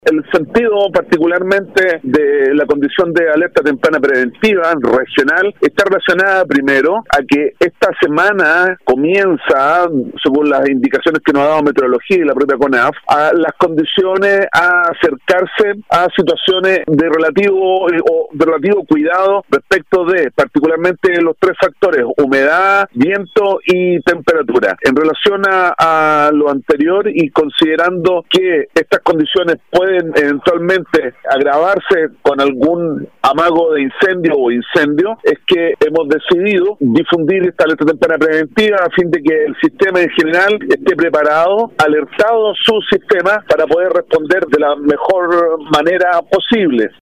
De acuerdo a esta información, la Dirección Regional de ONEMI Los Lagos declaró Alerta Temprana Preventiva Regional, lo que se constituye como un estado de reforzamiento de la vigilancia, según lo detalla el director de dicho organismo de emergencia, Alejandro Vergés.